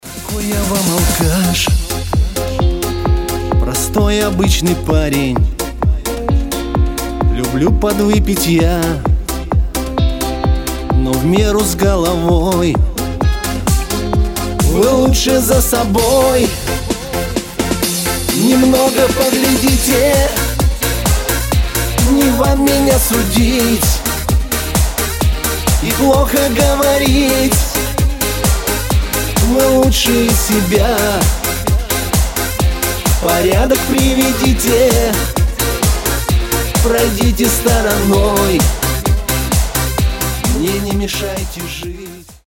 Рингтоны шансон